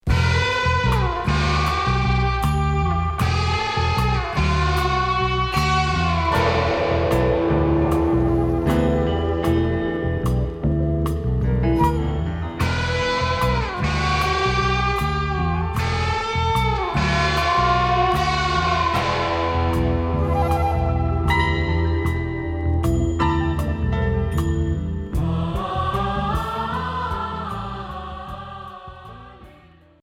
Progressif mystique Unique 45t retour à l'accueil